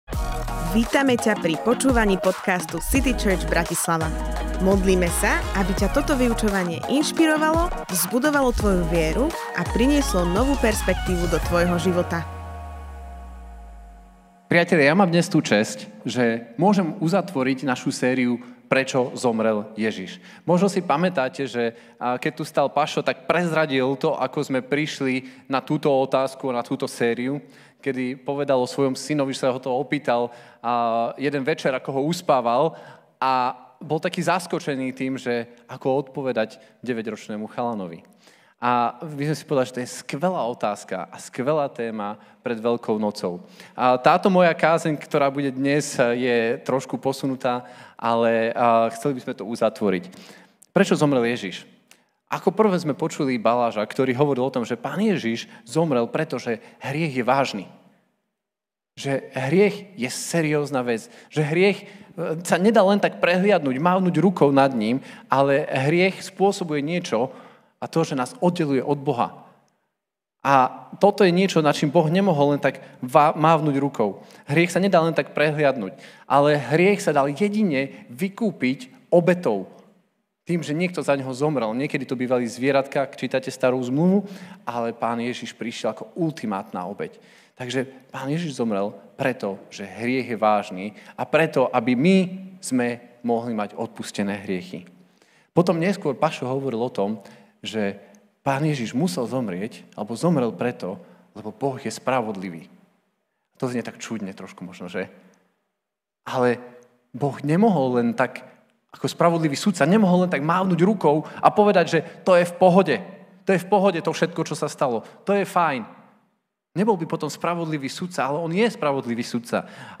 Kázeň týždňa Zo série kázní